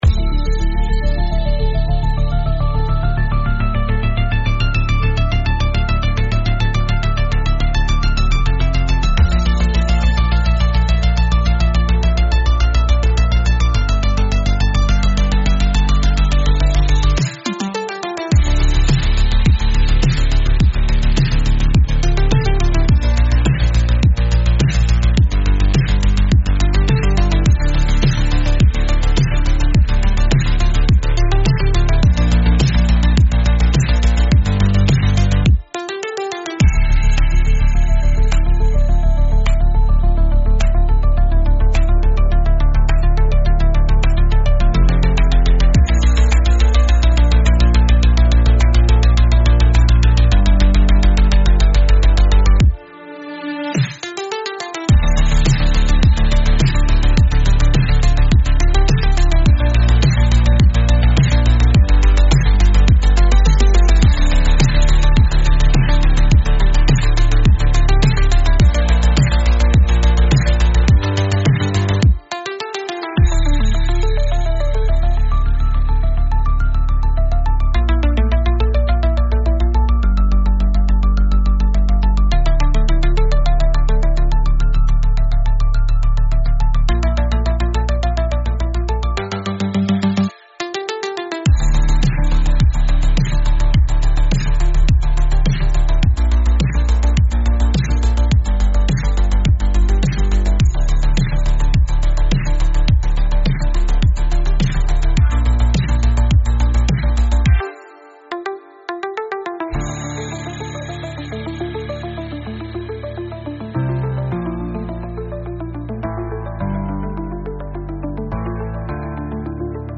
Instrumental -Real Liberty Media DOT xyz